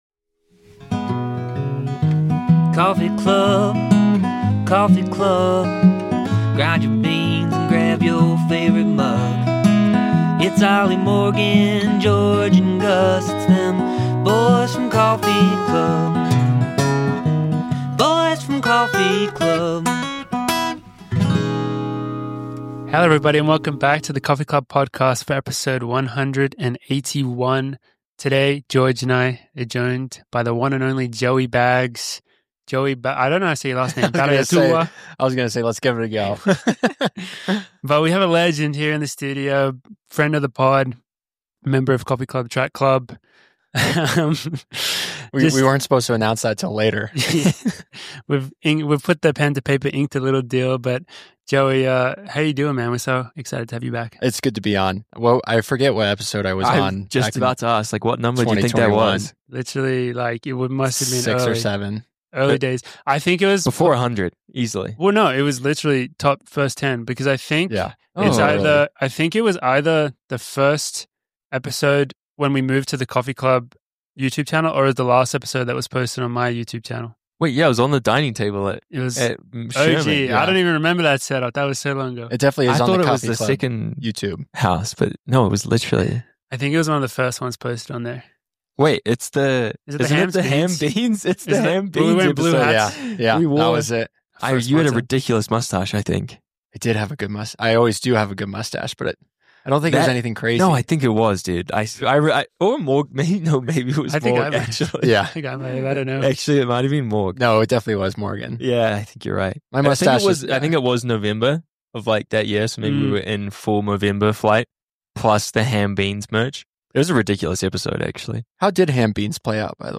A few runner bois sitting down drinking coffee and having a chat.